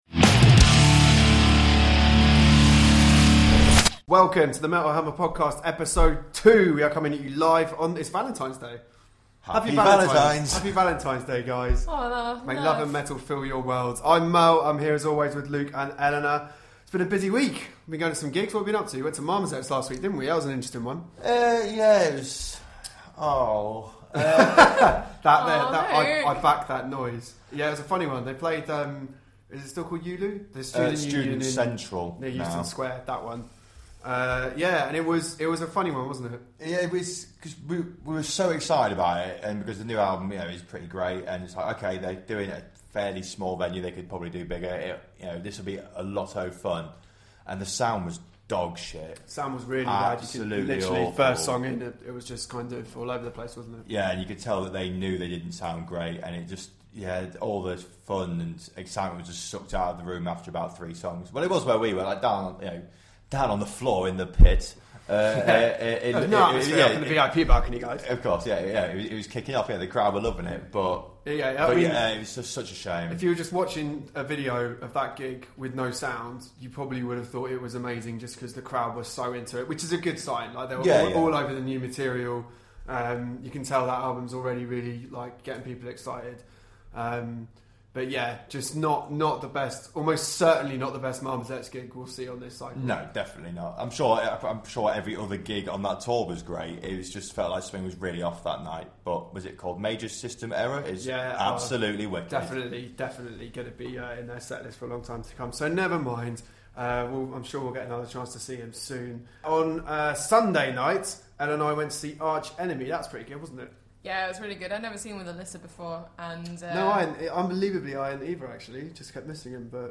We also have an exclusive interview with Judas Priest's Rob Halford and Ian Hill following Glenn Tipton's Parkinson's diagnosis.